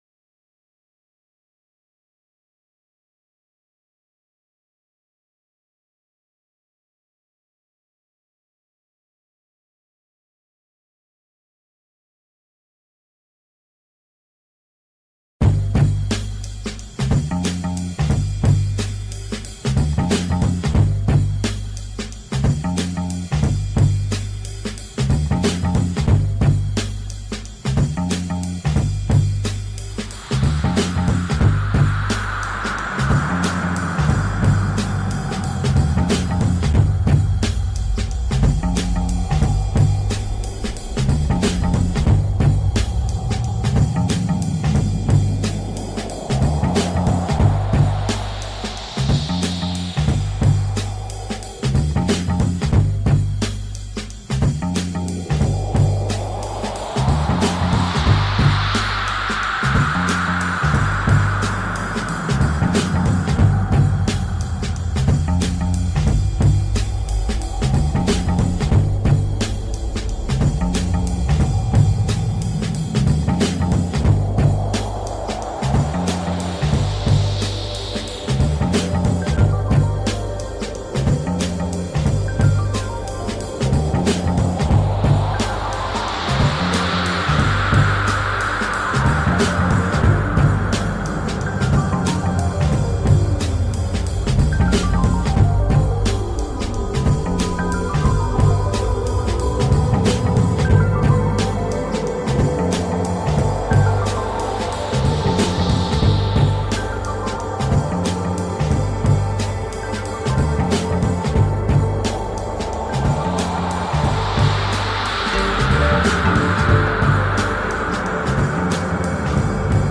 TIRANA CLOSING NIGHT / GRANATA CLUB